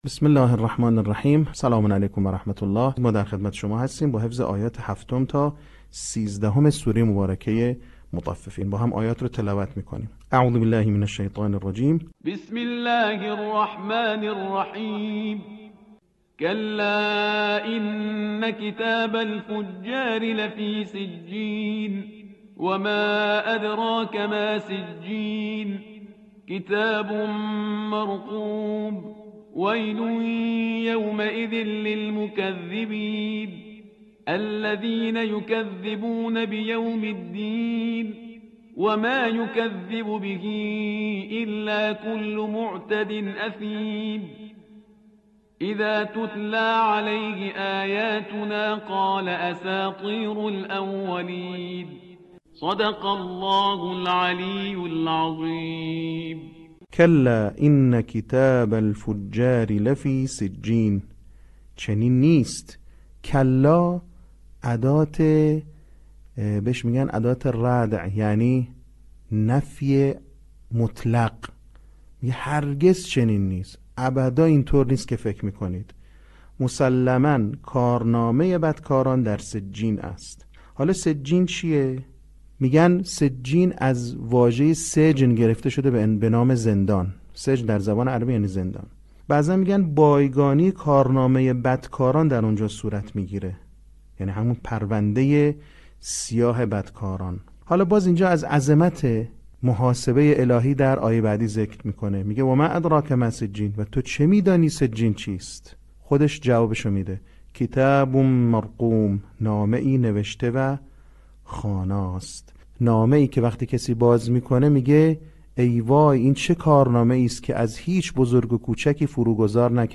صوت | بخش دوم آموزش حفظ سوره مطففین